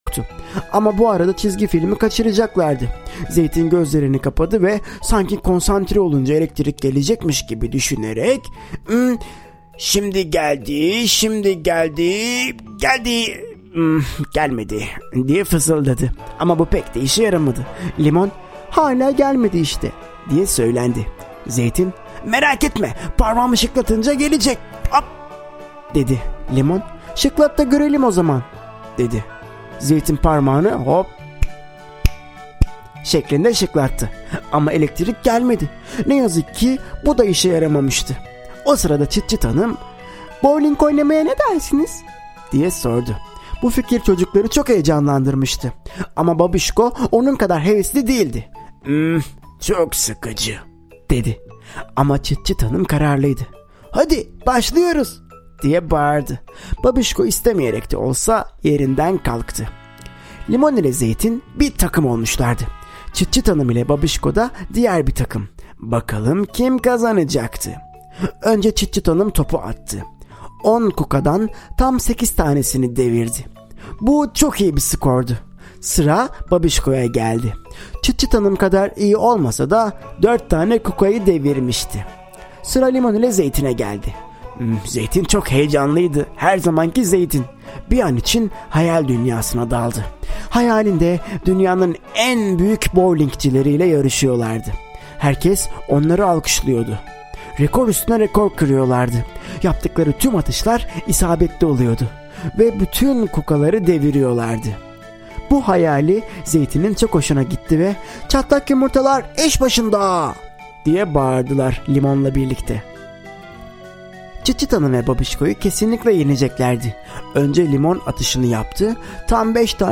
Limon ile Zeytin - Gitti Elektrik - Seslenen Kitap